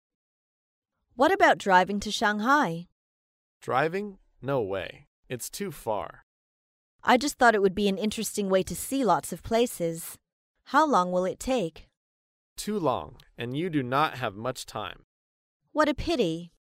在线英语听力室高频英语口语对话 第396期:长途驾车的听力文件下载,《高频英语口语对话》栏目包含了日常生活中经常使用的英语情景对话，是学习英语口语，能够帮助英语爱好者在听英语对话的过程中，积累英语口语习语知识，提高英语听说水平，并通过栏目中的中英文字幕和音频MP3文件，提高英语语感。